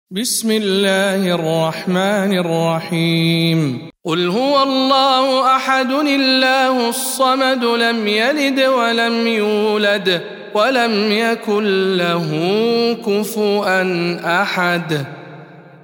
سورة الإخلاص - رواية الدوري عن الكسائي